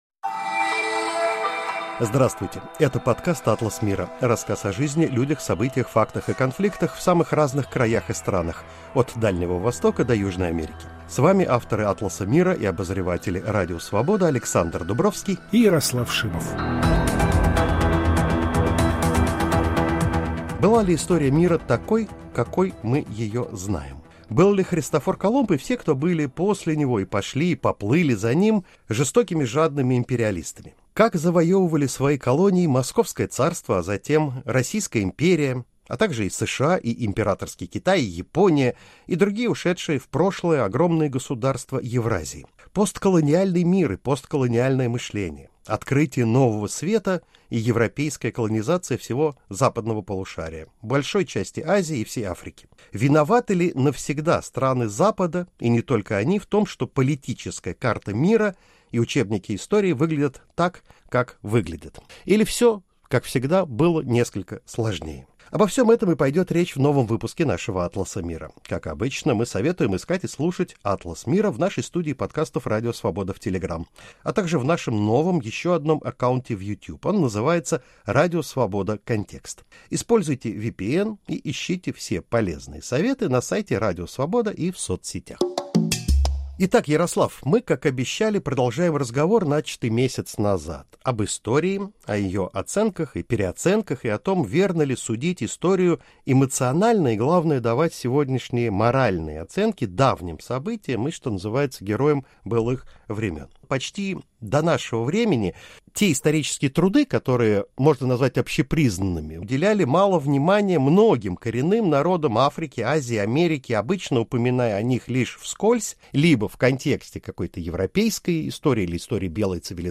Виноваты ли навсегда страны Запада в том, что карта мира и учебники выглядят такими, как сегодня? Мы продолжаем цикл бесед о переоценках истории.